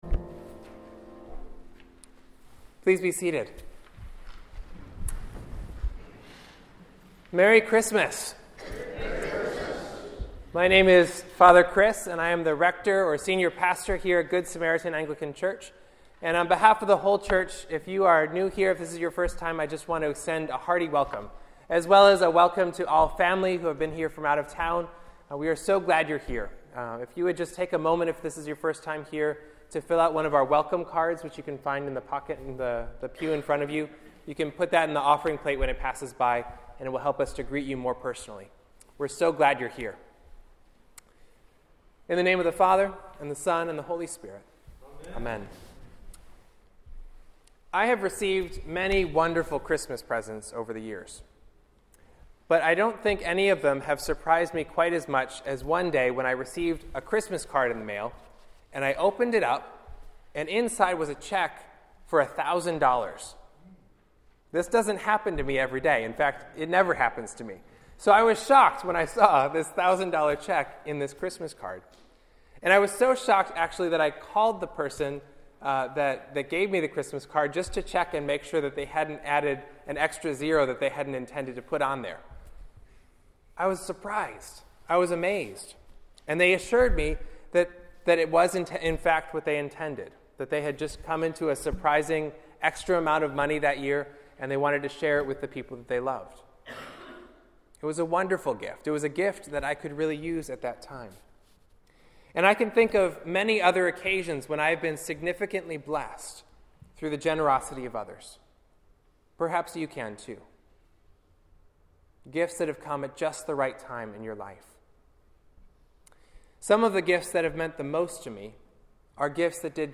Christmas Eve